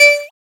edm-perc-33.wav